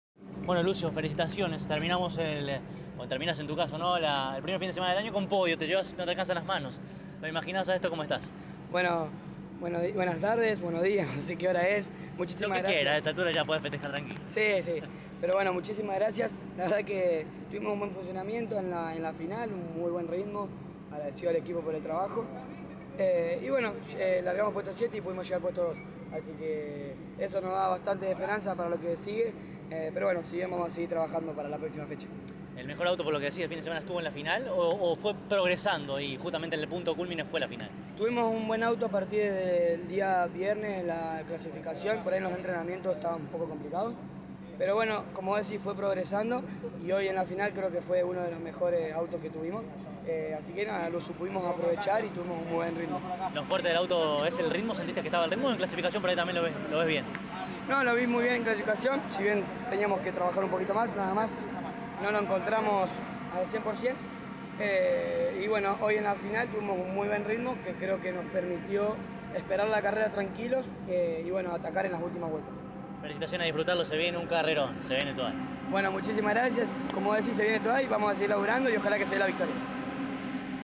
CÓRDOBA COMPETICIÓN estuvo allí presente y dialogó con los protagonistas más importantes al cabo de cada una de las finales.